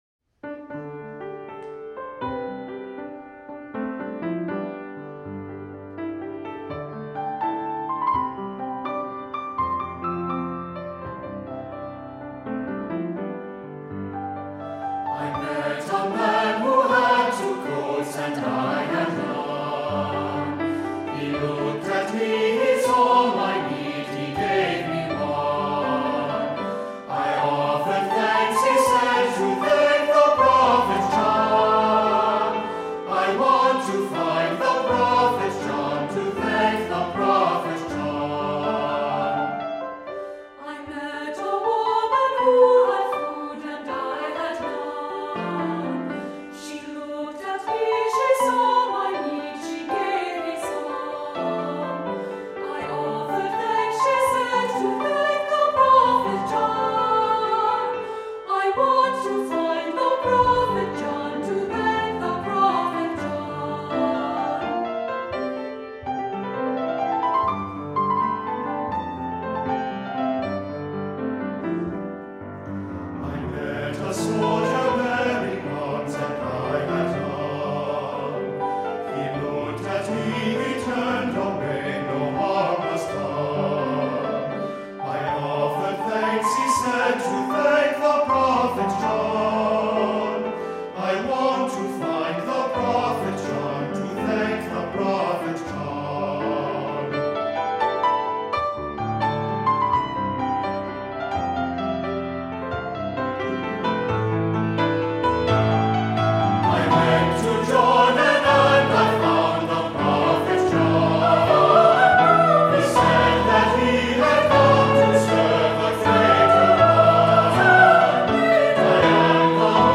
Voicing: Unison Choir; Descant